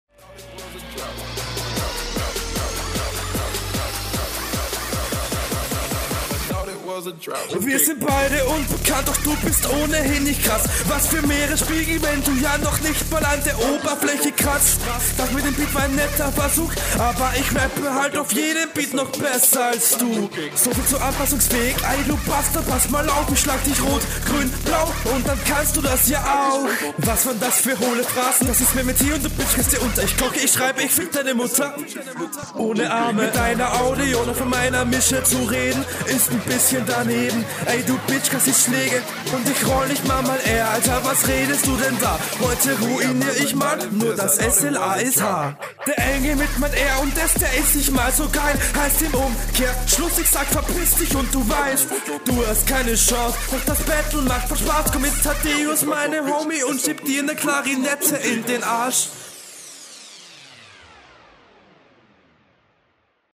Das klingt tatsächlich etwas angestrengter als beim Gegner, der Akzent fällt hier auch noch etwas …